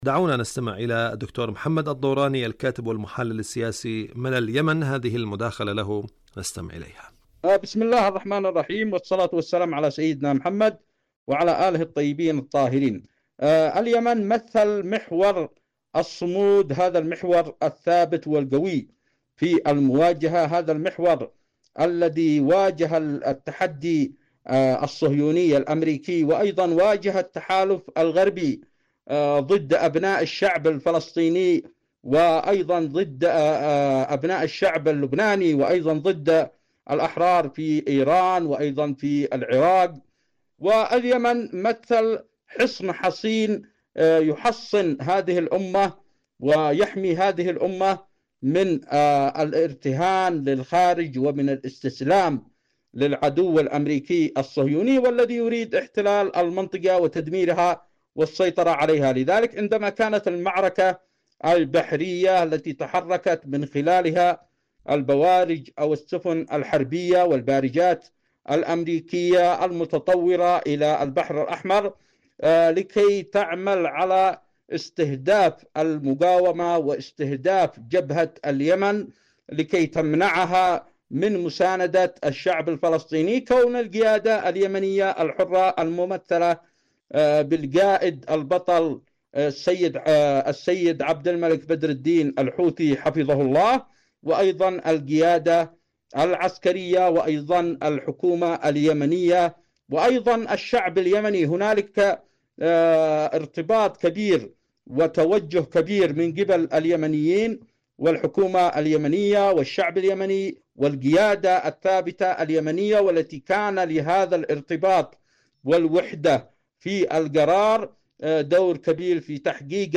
مقابلة
إذاعة طهران- حدث وحوار: مقابلة إذاعية